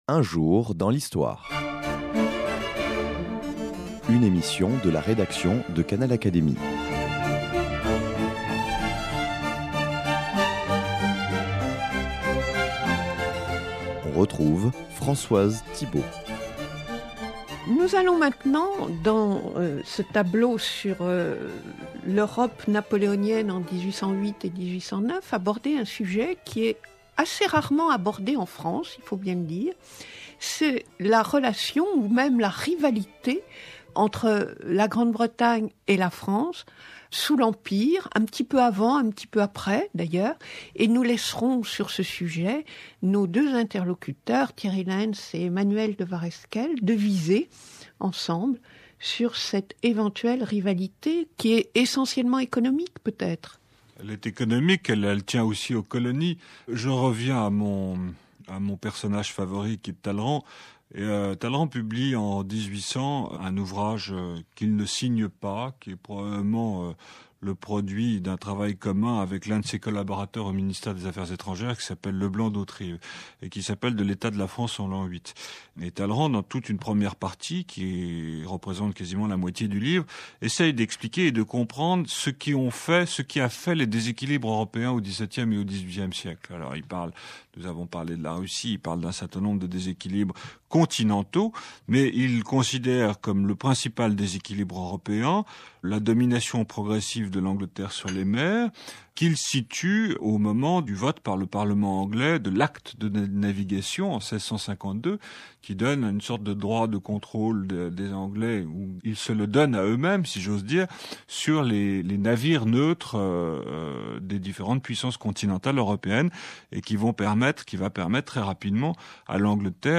Ecoutez dans cette émission Thierry Lentz et Emmanuel de Waresquiel s’exprimant sur la rivalité franco-britannique qui fut un élément clé de l’année 1809.
Les historiens Thierry Lentz et Emmanuel de Waresquiel reviennent sur les relations diplomatiques houleuses entre les deux pays.